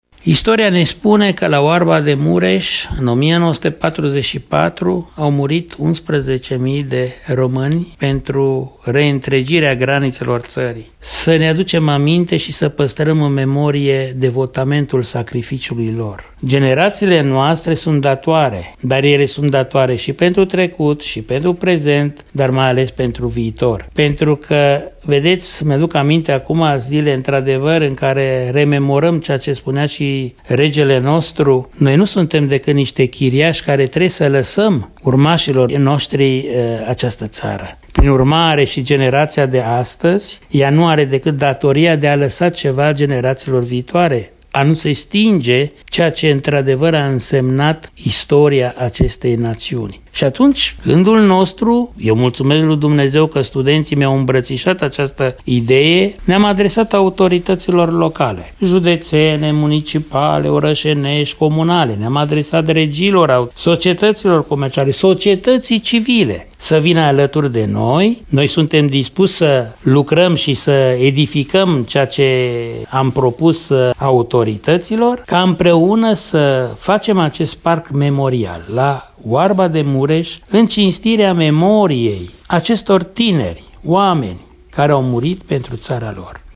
intervievat